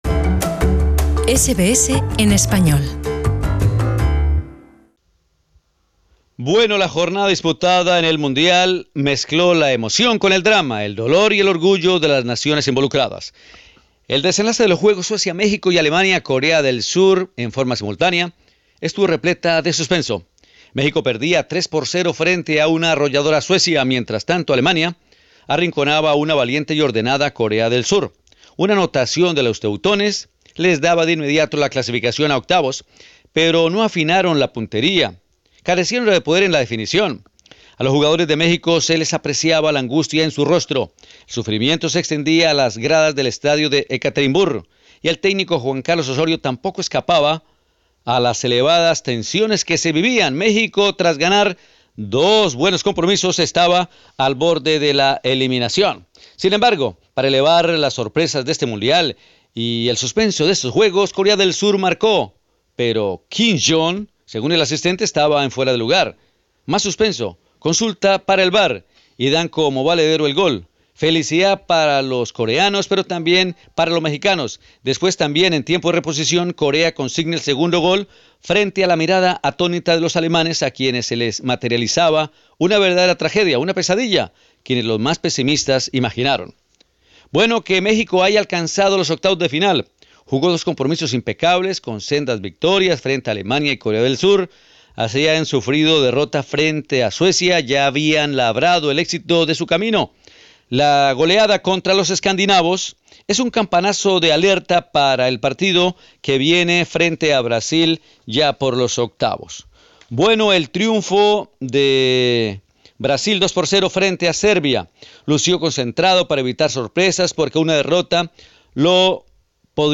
Escucha LO BUENO, LO MALO Y LO FEO con nuestro enviado especial a Rusia-2018